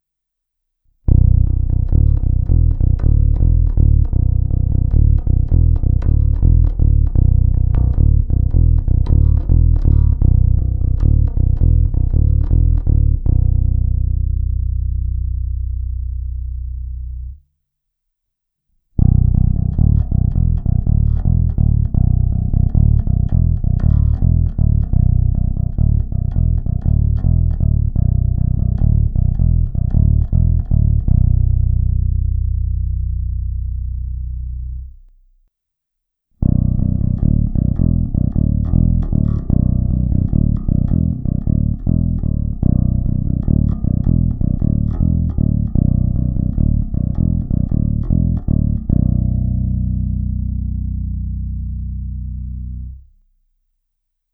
Zvuk má výrazný moderní charakter, nejspíše i díky dvoucívkovým snímačů mi zvuk přišel nepatrně zastřenější, ale není problém lehce přitlačit na korekcích výšek, dodat tam tak průzračnost a vzdušnost.
Není-li uvedeno jinak, následující nahrávky jsou provedeny rovnou do zvukové karty.